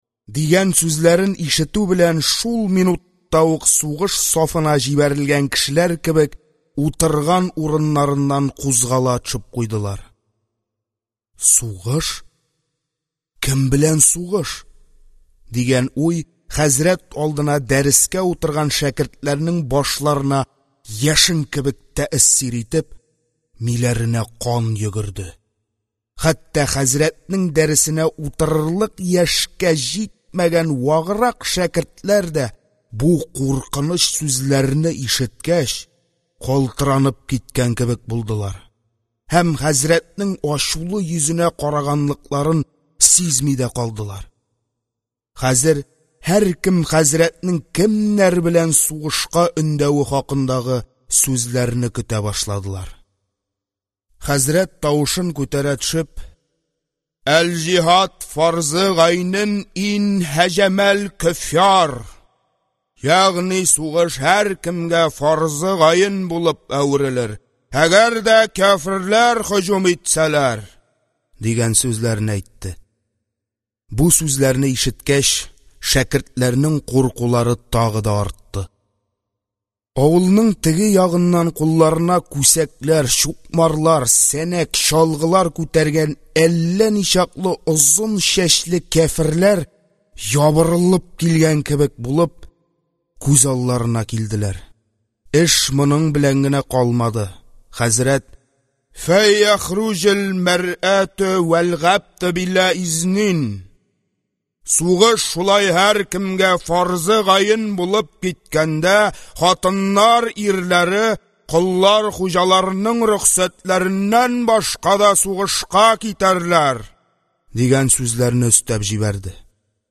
Аудиокнига Тормыш баскычлары | Библиотека аудиокниг
Прослушать и бесплатно скачать фрагмент аудиокниги